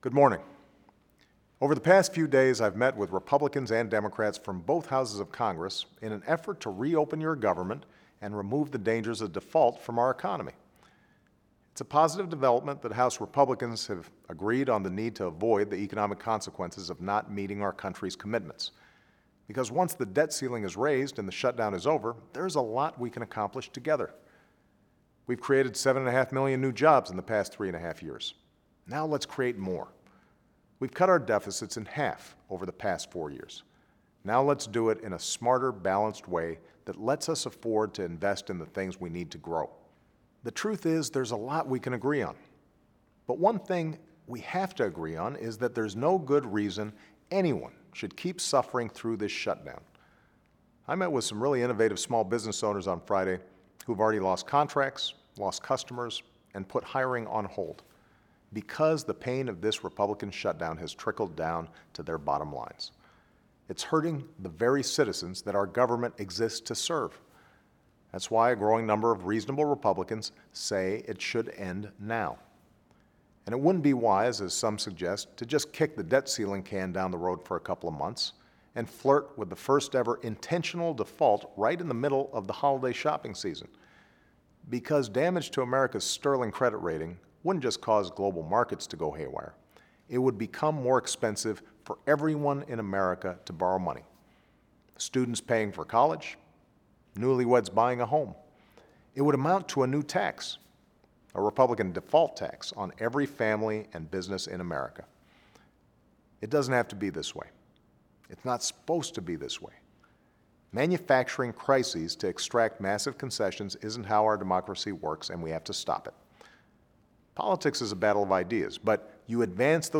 In this week’s address, President Obama discussed his meetings with members of Congress of both parties and the ongoing effort to reopen the government and remove the danger of default from our economy. The President urged Congress to pass a budget, put people back to work, and end this shutdown. In his message, the President also called for paying our bills, and preventing an economic shutdown.